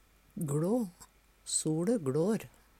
DIALEKTORD PÅ NORMERT NORSK gLå gå ned Infinitiv Presens Preteritum Perfektum gLå gLår gLådde gLådd Eksempel på bruk SoLe gLår.